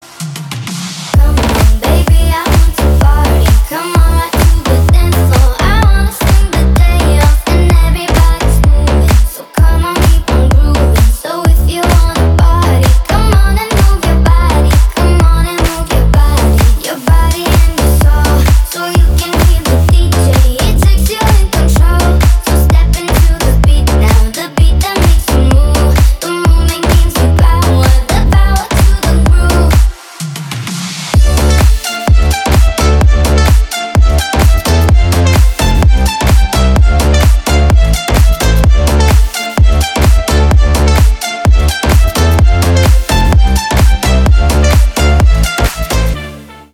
• Качество: 320, Stereo
ритмичные
женский голос
Club House
басы
качающие
Стиль: club house